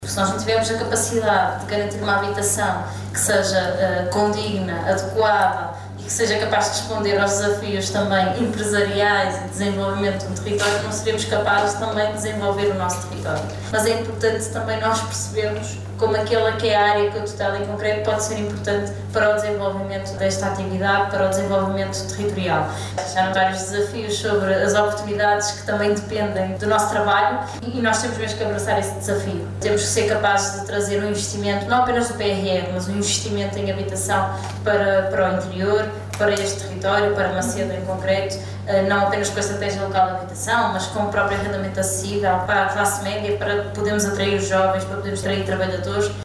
Declarações à margem da abertura oficial da 38º Edição da Feira Empresarial de S. Pedro, em Macedo de Cavaleiros.